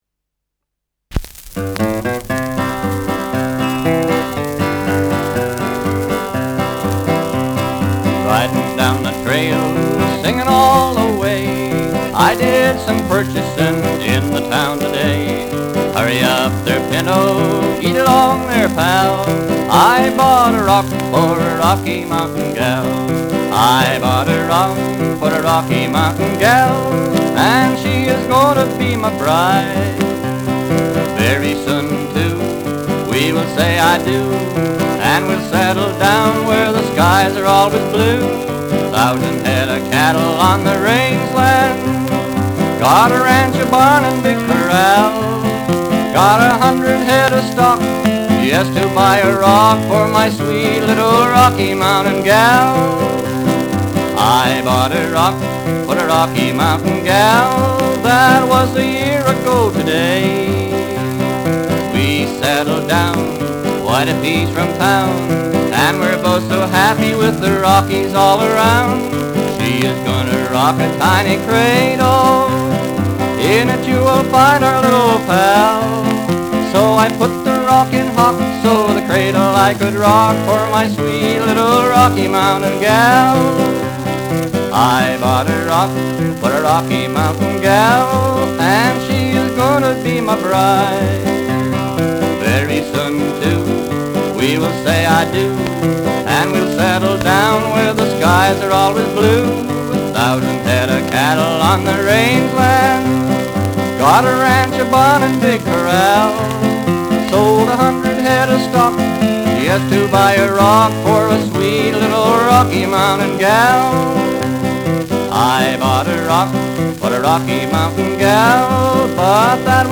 Schellackplatte
Knistern